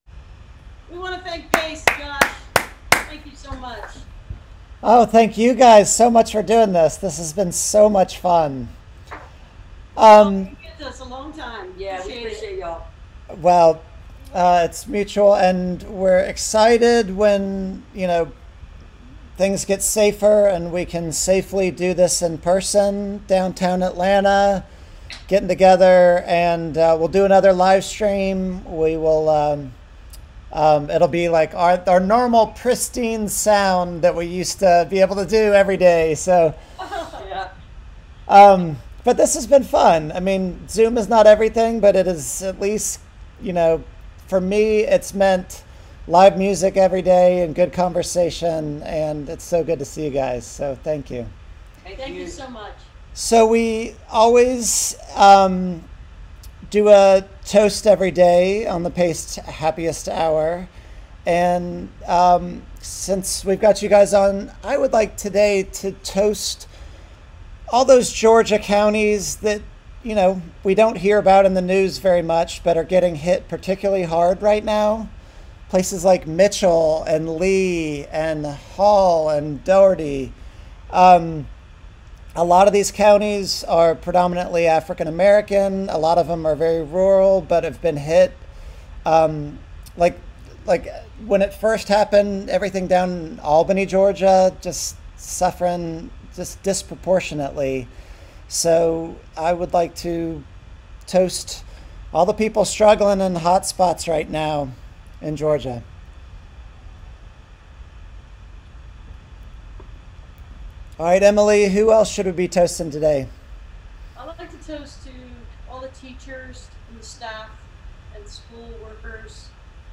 (captured from the web broadcast, including audio issues at times)
09. interview (4:10)